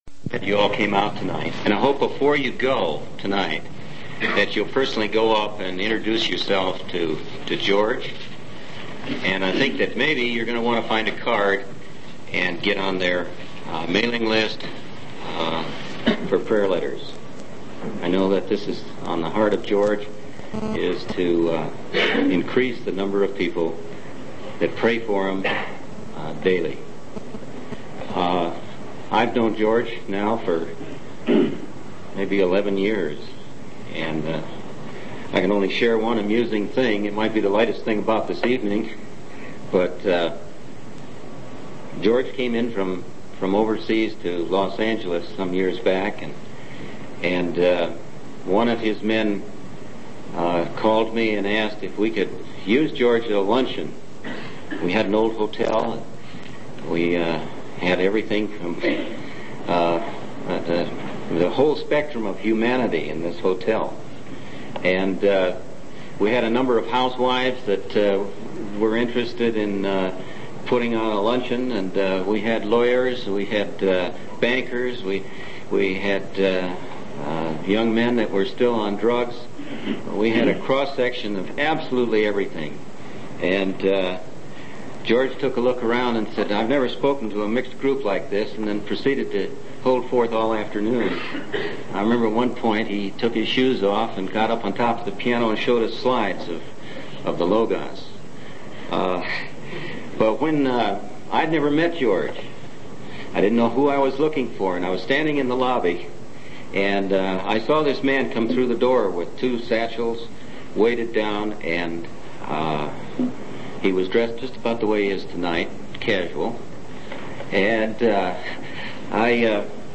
In this sermon, the speaker emphasizes the grace, forgiveness, and power available to sinners through Jesus Christ.